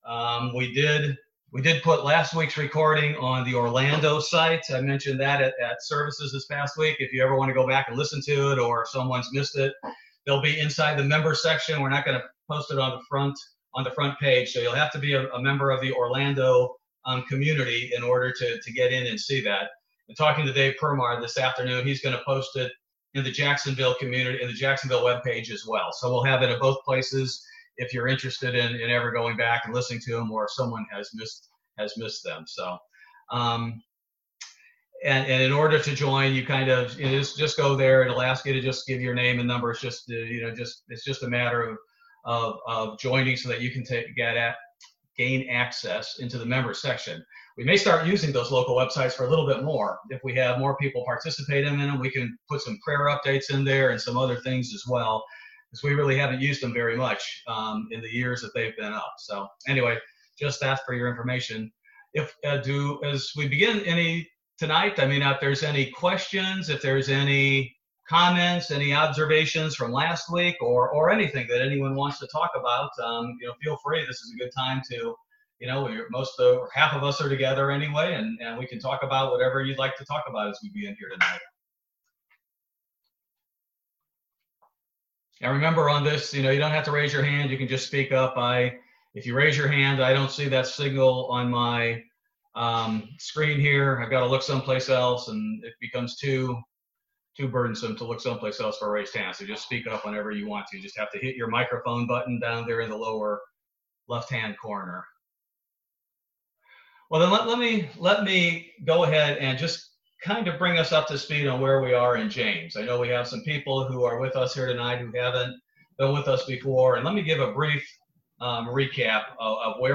This Bible Study is on James Chapter 3. Members from the Orlando and Jacksonville, Florida congregations were in attendance on Zoom.